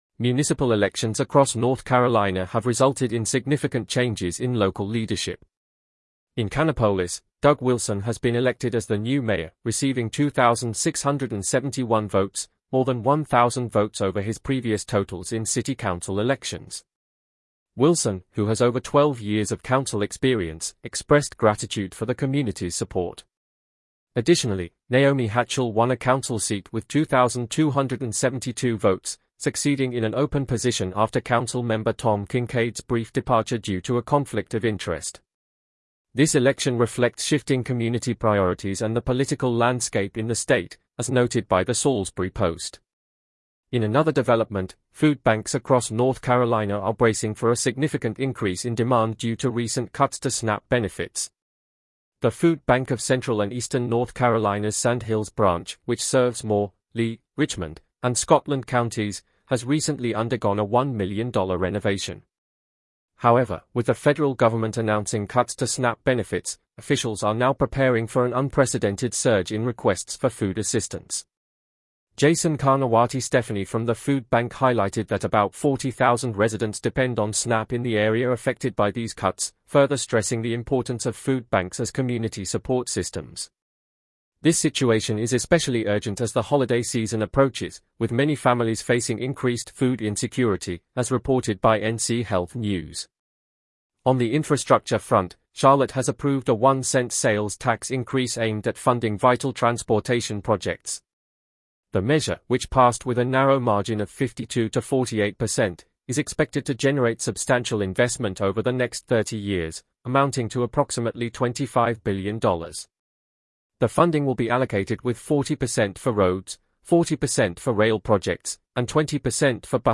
North Carolina News Summary